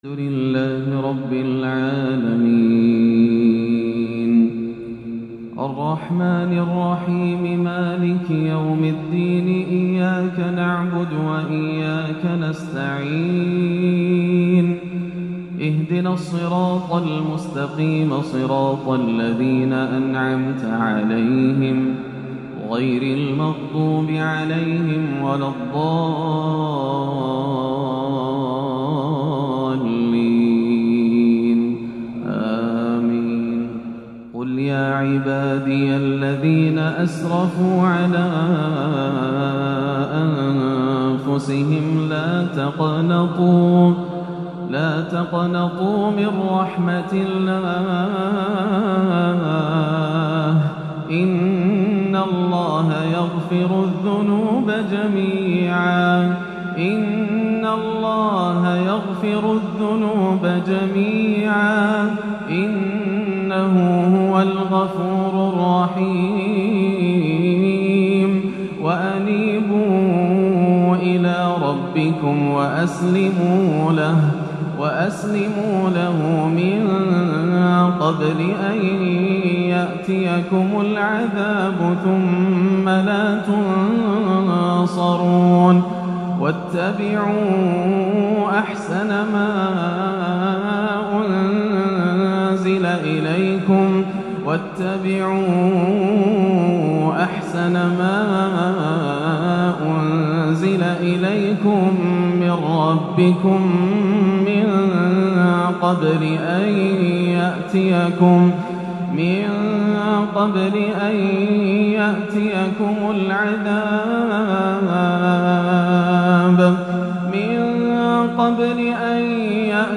أواخر الزمر - عشائية نادرة جمعت أجمل ما تمنى محبو شيخنا الغالي من الأداء والتأثير - الإثنين 25-11 > عام 1437 > الفروض - تلاوات ياسر الدوسري